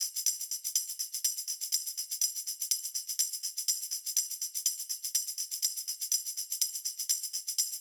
08 Tambourine.wav